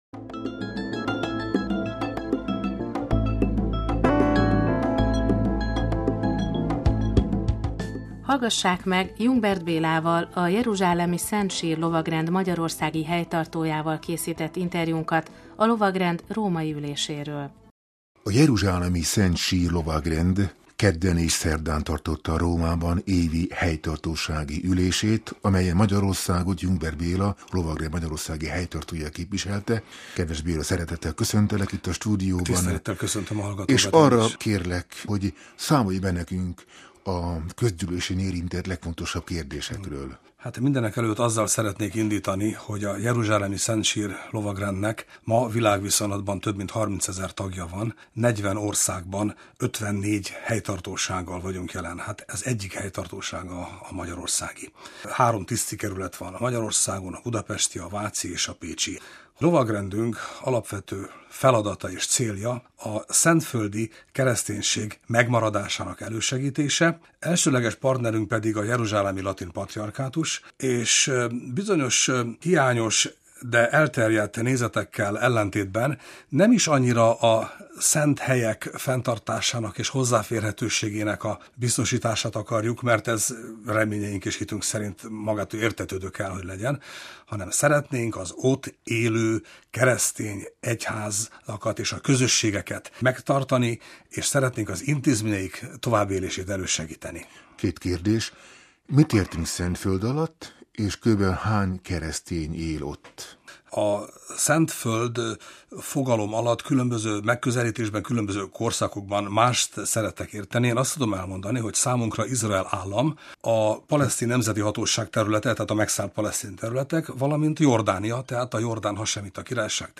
A Vatikáni Rádiónak adott interjújában beszámolt a lovagrend szentföldi tevékenységéről, hogyan segíti az ott élő keresztényeket. Továbbá tájékoztatott a szentföldi keresztények számáról, felekezeti megoszlásáról.